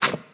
step-1.ogg